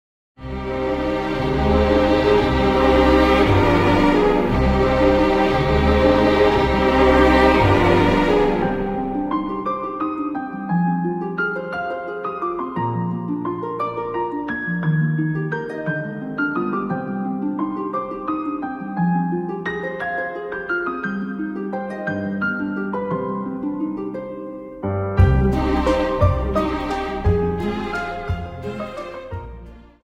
Viennese Waltz 59 Song